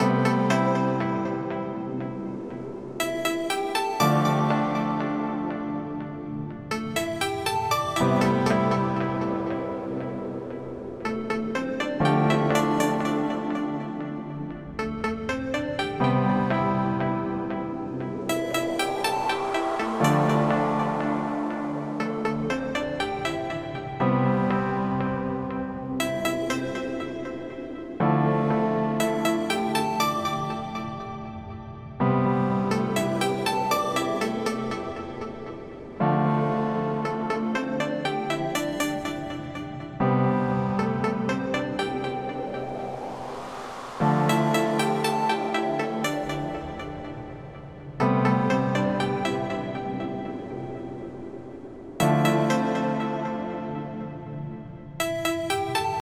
Ambient Electro Loop.mp3